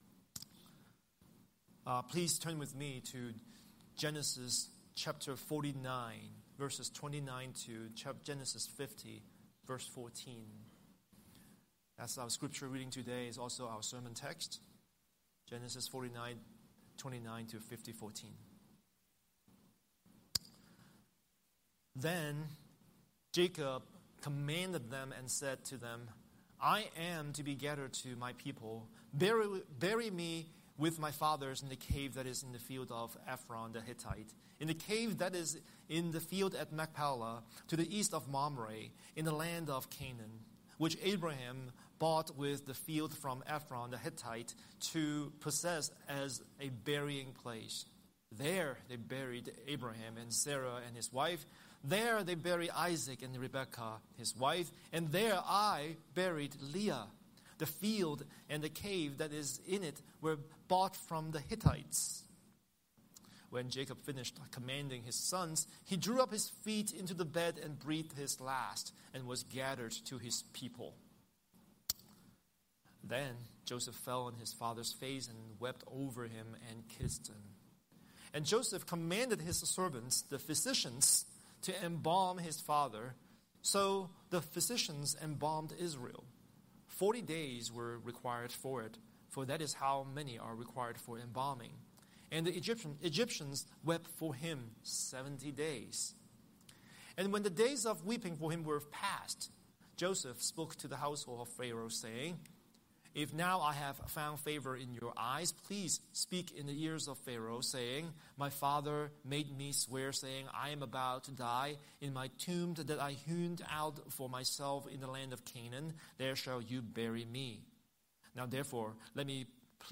Scripture: Genesis 49:29–50:14 Series: Sunday Sermon